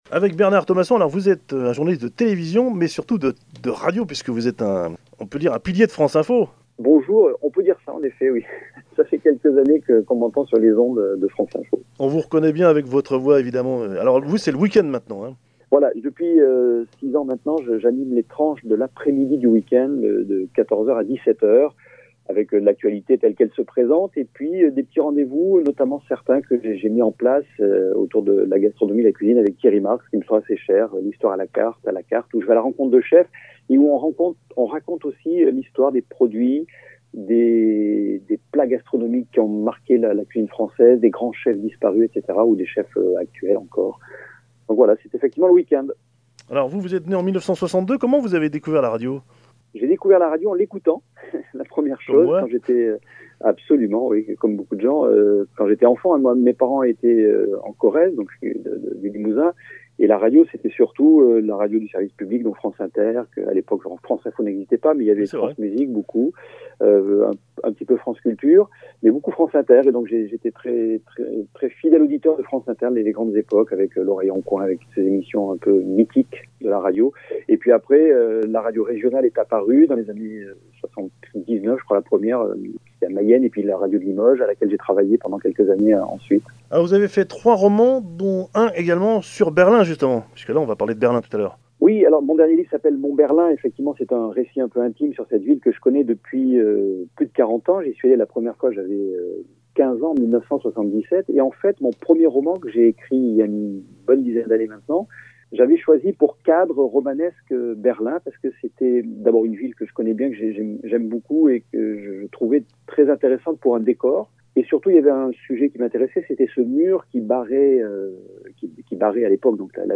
Interview de Bernard Thomasson (4 parties)